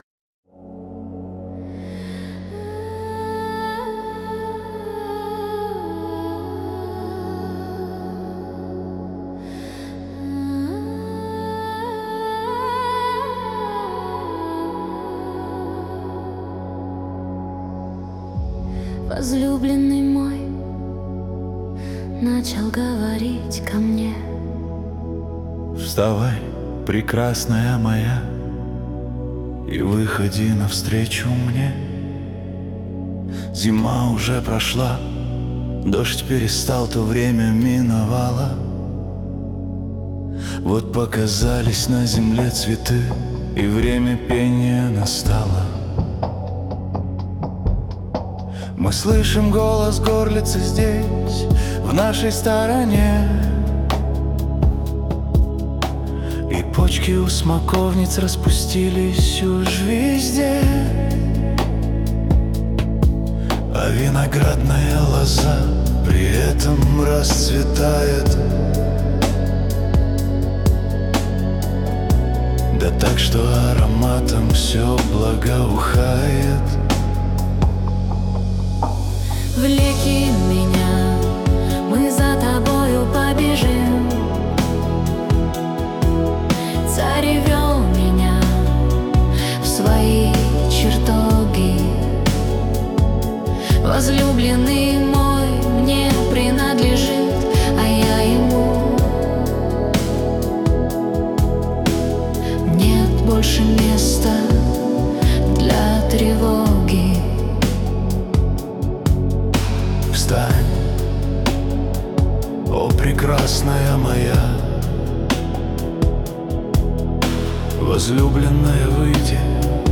песня ai
13 просмотров 27 прослушиваний 3 скачивания BPM: 72